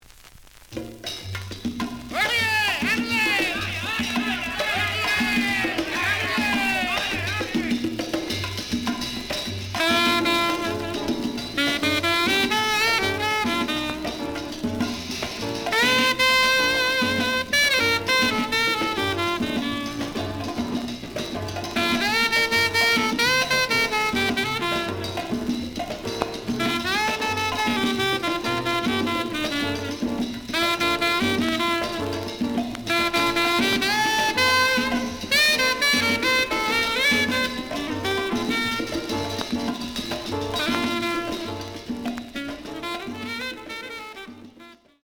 The audio sample is recorded from the actual item.
●Genre: Bop
Edge warp.